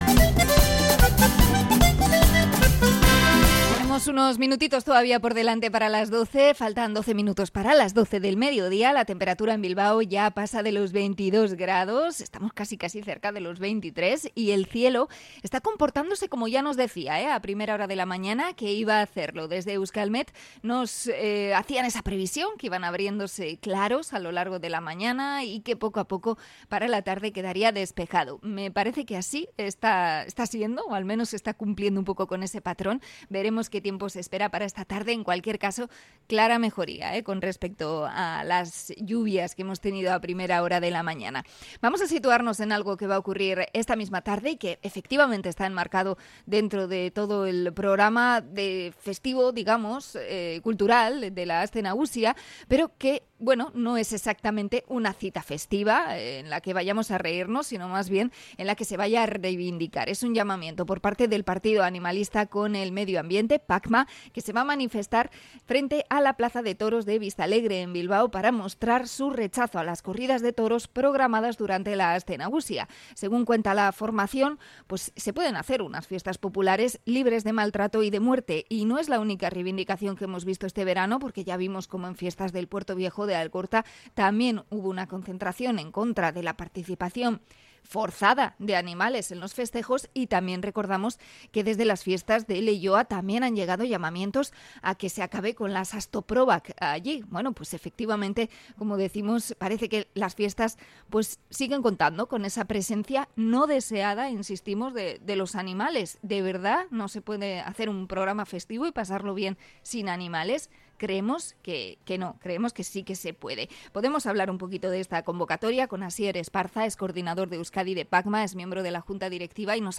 Entrevista a PACMA por su manifestación antitaurina